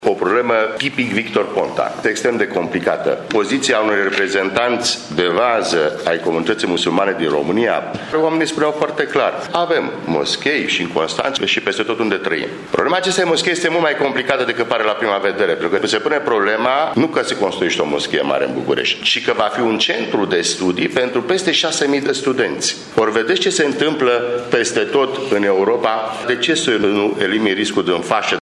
Declarația a fost făcută ieri la Tîrgu-Mureș de copreședintele PNL Vasile Blaga, cu referire la alocarea de către Guvernul Ponta a terenului pentru construirea la București a celei mai mari moschei din Europa.
Aici ar urma să fie construit, de asemenea, un centru de studii pentru 6.000 de tineri musulmani. Prin urmare, riscul trebuie eliminat din fașă, susține liderul PNL, Vasile Blaga: